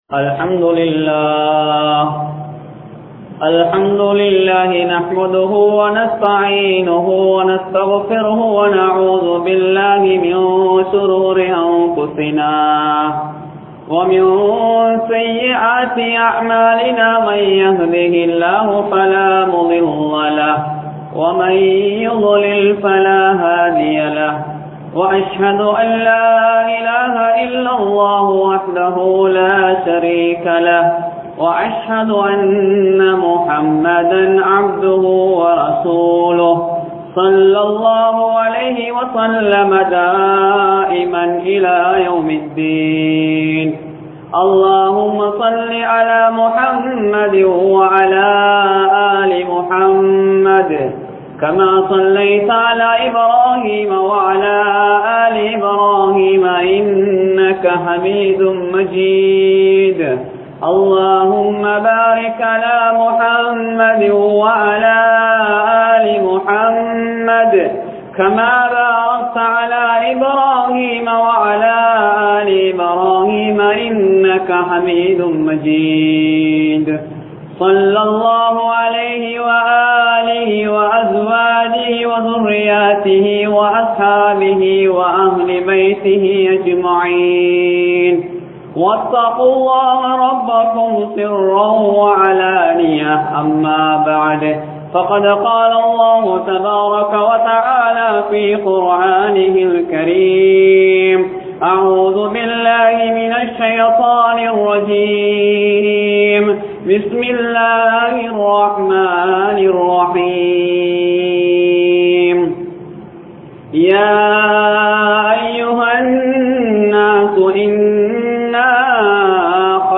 Majmaulkareeb Jumuah Masjith